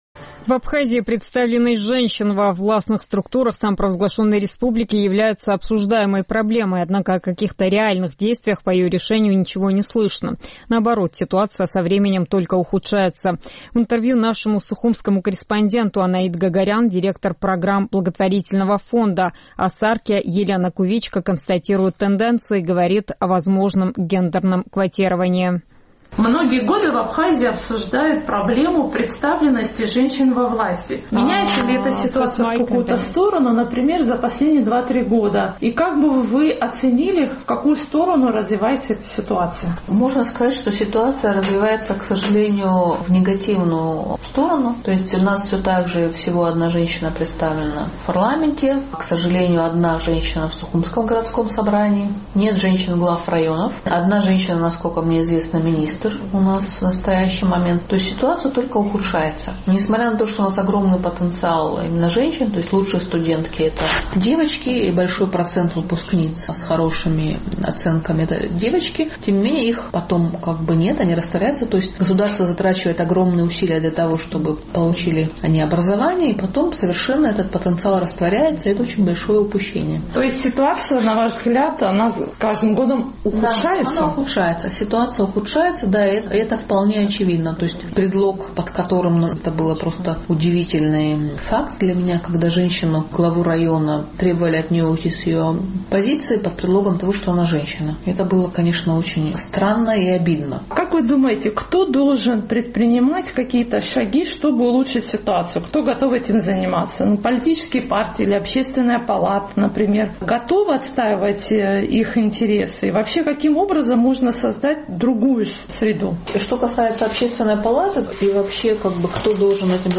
В Абхазии представленность женщин во власти является обсуждаемой проблемой. Однако о каких-то реальных действиях по ее решению ничего не слышно. В интервью